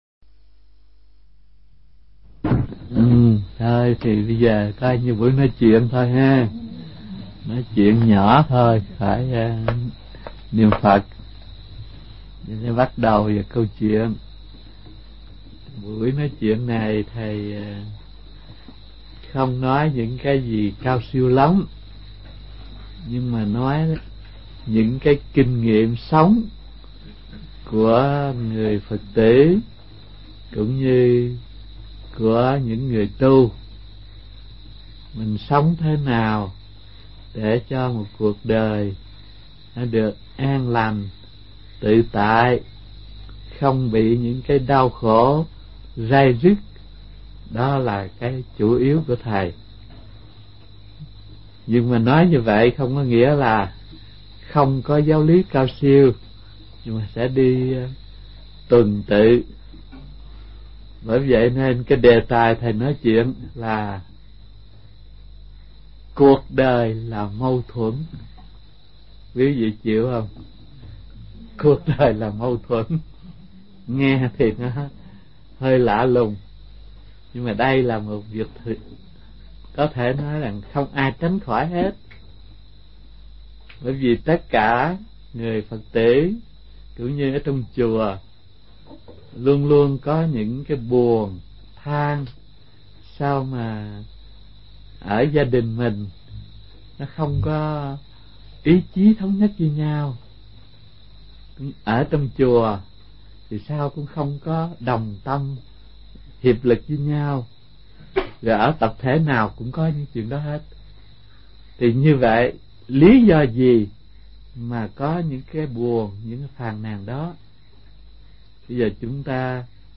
Nghe mp3 Pháp Âm Cuộc Đời Là Mâu Thuẫn – Hòa Thượng Thích Thanh Từ